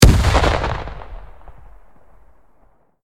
medium-explosion-3.ogg